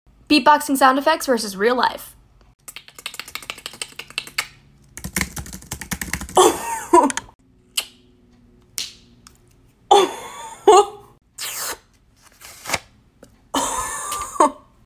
That last one though🗒Beatboxing sound effects vs real life pt. 2 🗣